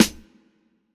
Medicated Snare 22.wav